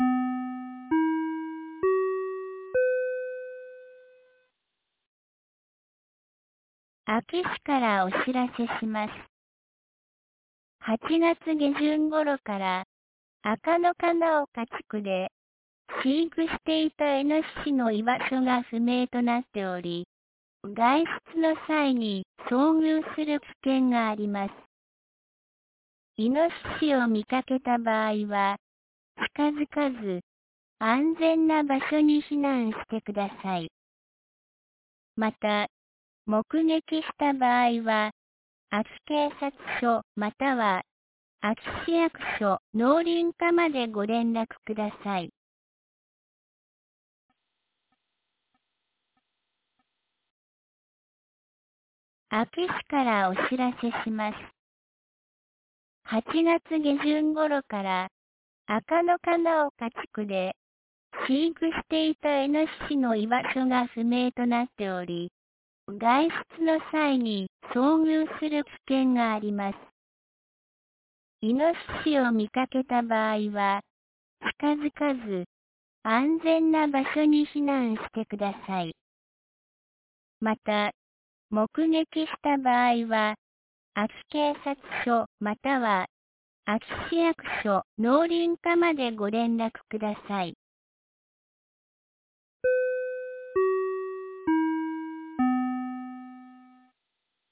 2024年09月13日 07時46分に、安芸市より赤野、穴内へ放送がありました。